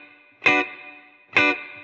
DD_TeleChop_130-Bmin.wav